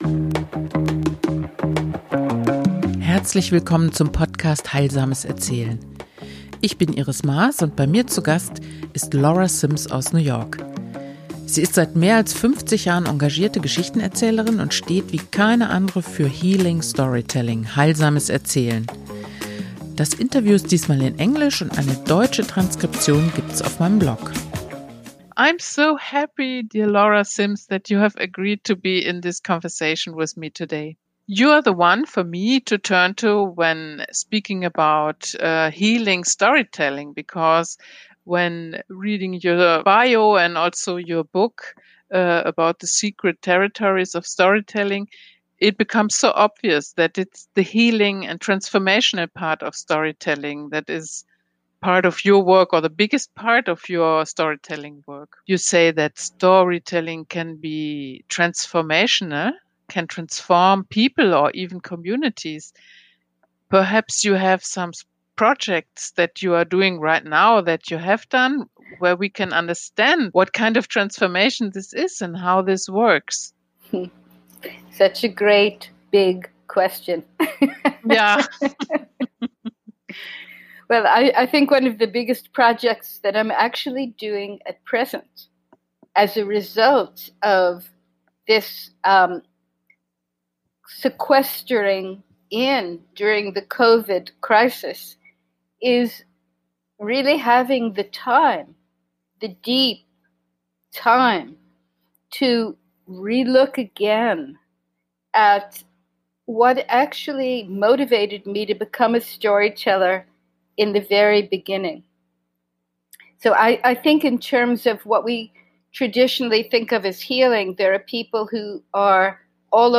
Das Interview ist in Englisch.